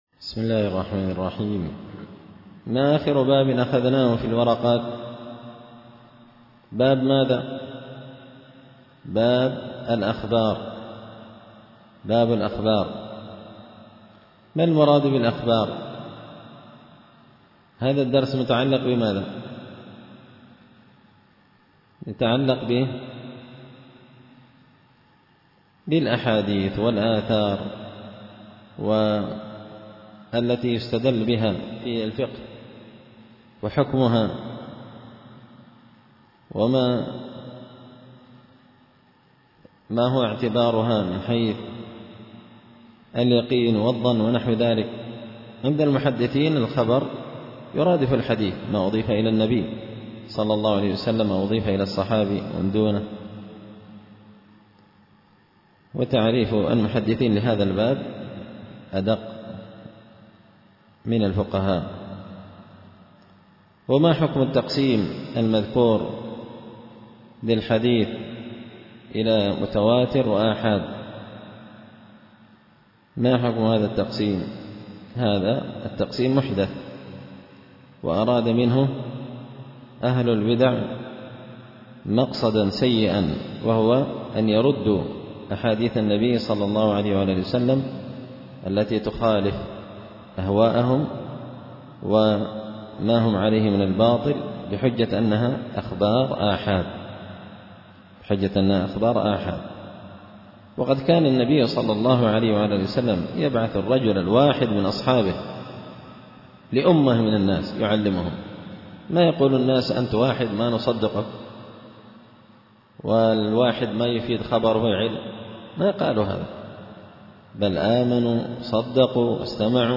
التعليقات على نظم الورقات ـ الدرس 47
دار الحديث بمسجد الفرقان ـ قشن ـ المهرة ـ اليمن